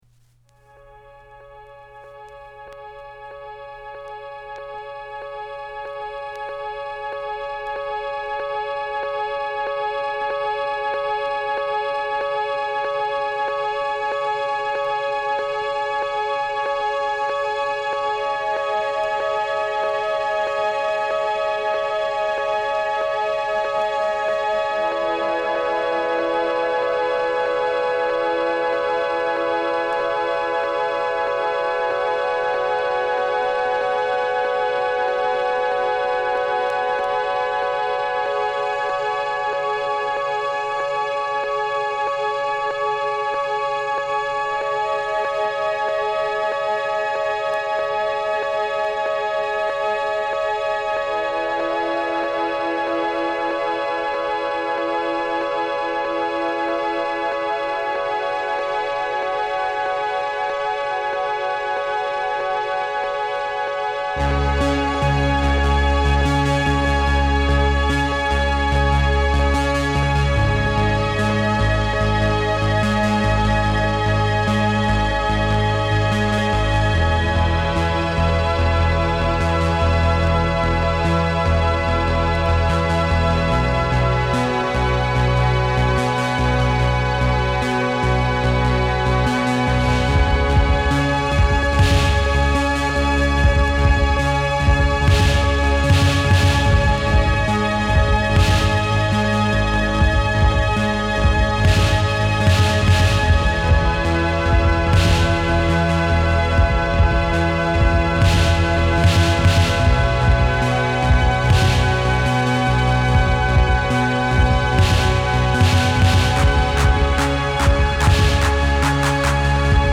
Beautiful and monumental ambient synth E.B.M.